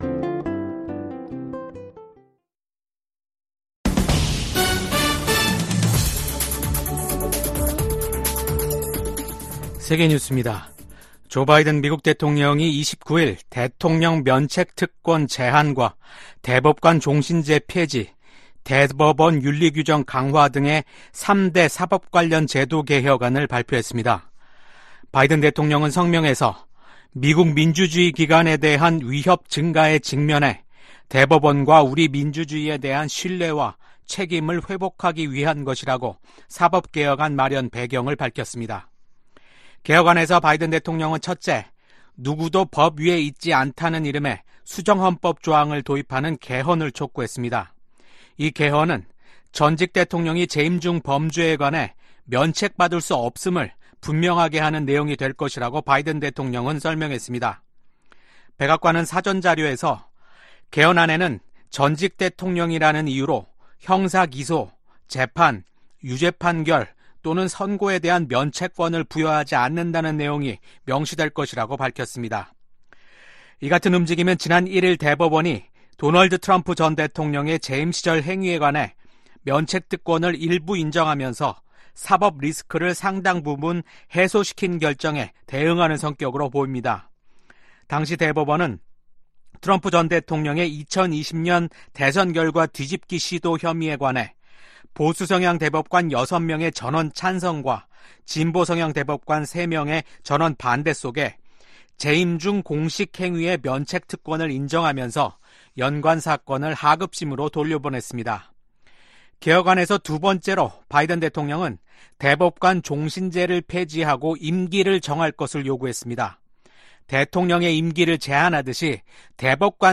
VOA 한국어 아침 뉴스 프로그램 '워싱턴 뉴스 광장' 2024년 7월 31일 방송입니다. 미국이 핵확산금지조약(NPT) 평가 준비회의에서 북한의 완전한 비핵화가 목표라는 점을 재확인했습니다. 미국과 일본, 인도, 호주 4개국 안보협의체 쿼드(Quad) 외무장관들이 북한의 탄도미사일 발사와 핵개발을 규탄했습니다. 북한 주재 중국대사가 북한의 ‘전승절’ 기념 행사에 불참하면서, 북한과 중국 양국 관계에 이상 징후가 한층 뚜렷해지고 있습니다.